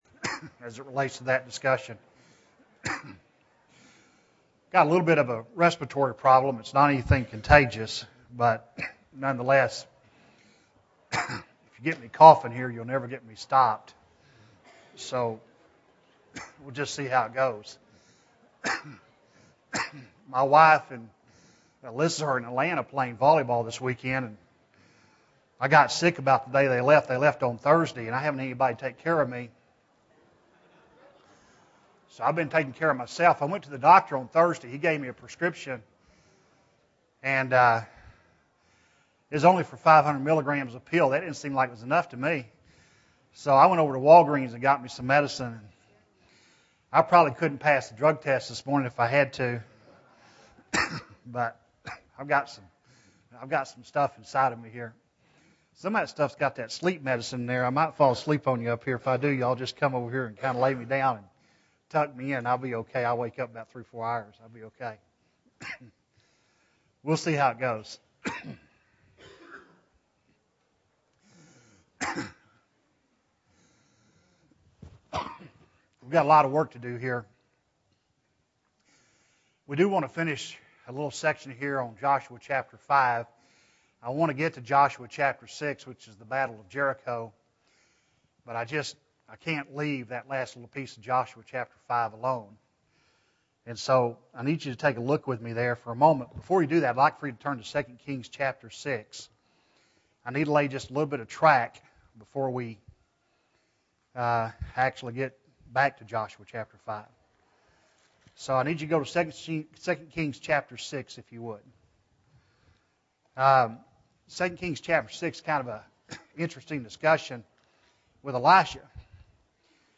Joshua 6 (6 of 14) – Bible Lesson Recording
Sunday AM Bible Class